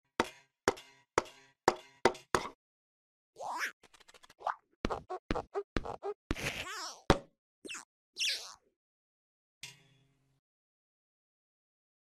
🔊 Pixar Foley sound design sound effects free download